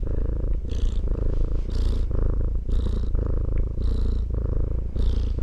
SFX_Cat_Purr_01.wav